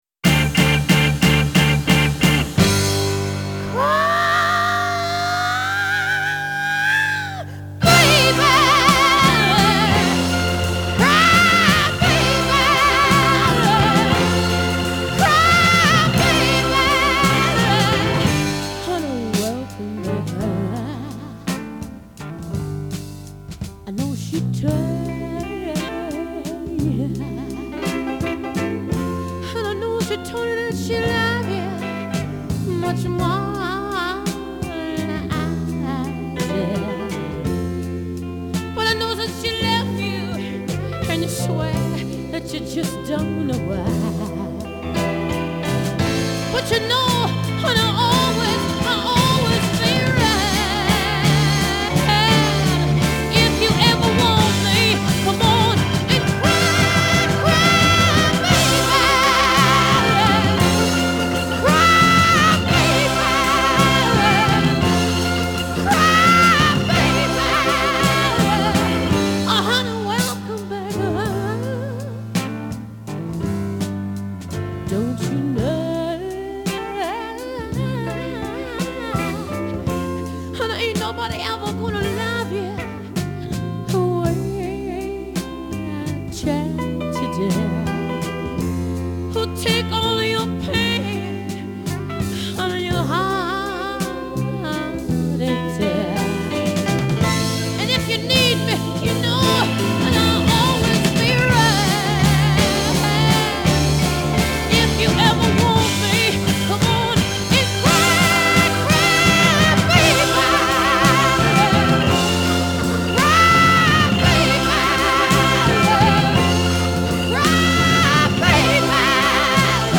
Жанр: Blues Rock